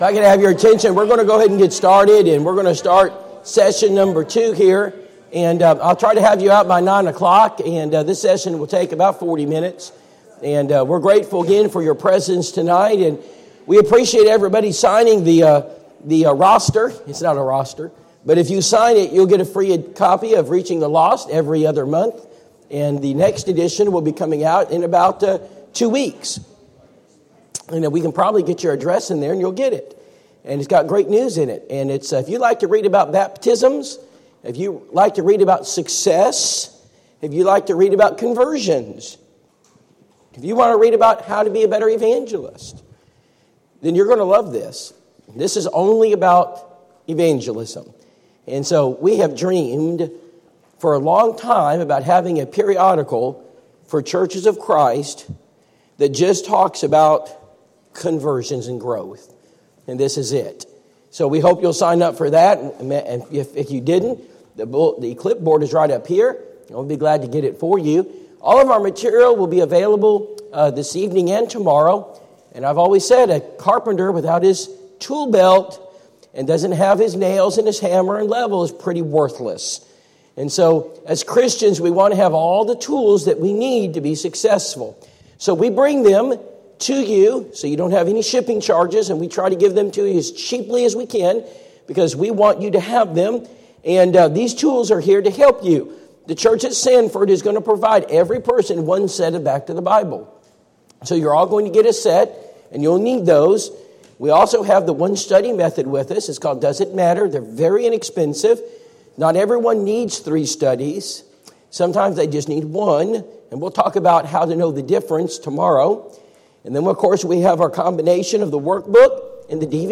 Personal Evangelism Seminar Service Type: Personal Evangelism Seminar Preacher